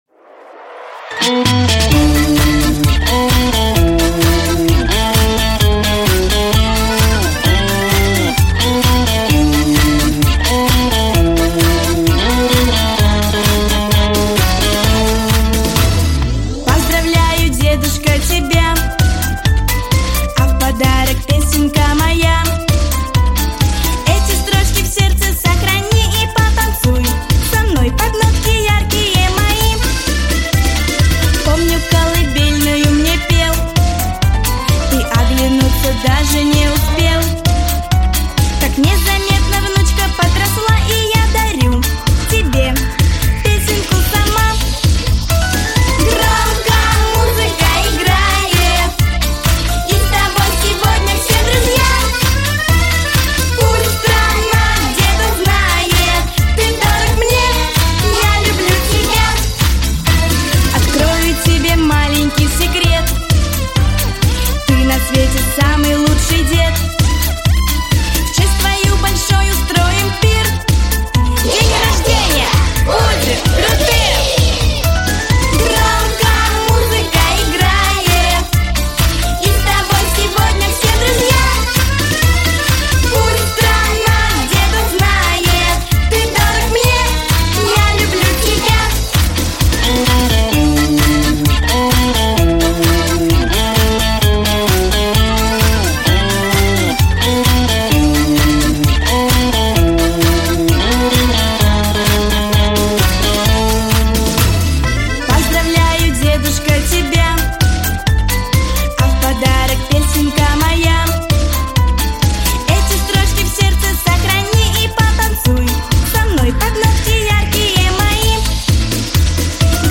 Главная / Песни для детей / Песни про дедушку